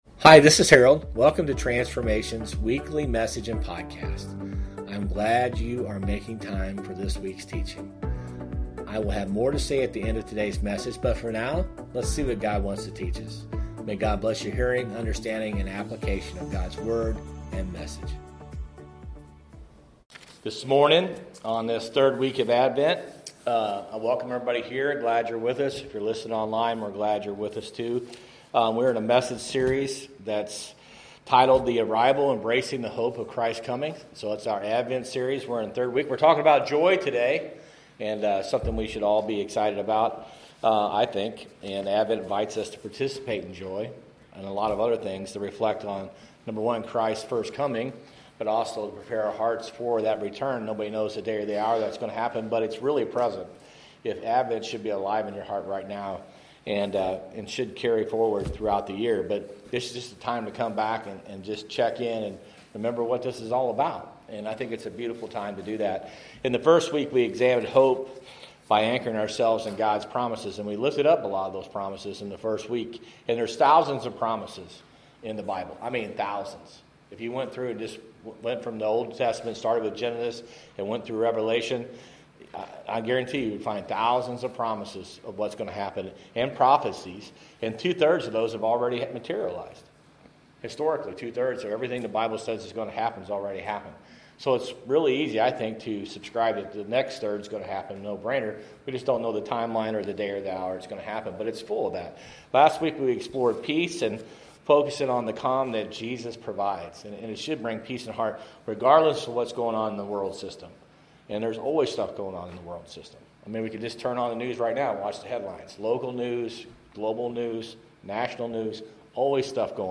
Sermons | Transformation Church